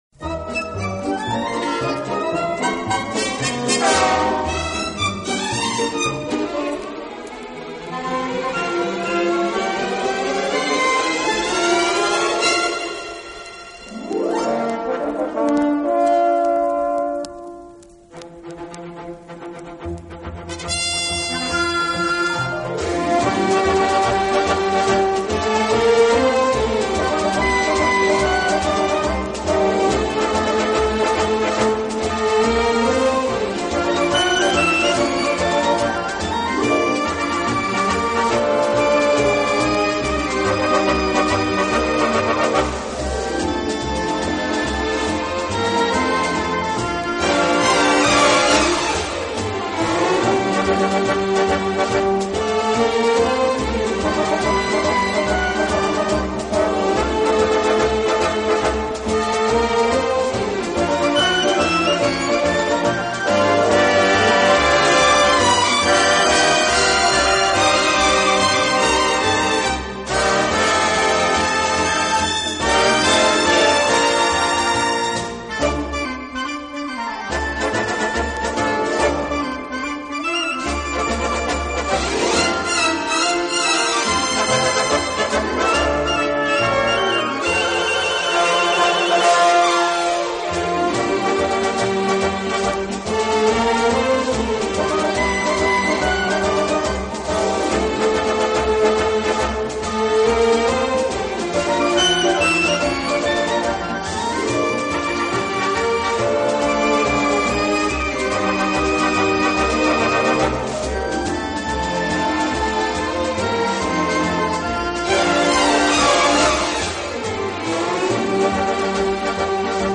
【轻音乐专辑】
上世纪50-60年代红极一时，乐队演奏就象一副美丽的画卷。
乐队具有完整的管乐器组和弦乐器
组，非常擅长将原曲中最优美的部分表现出来。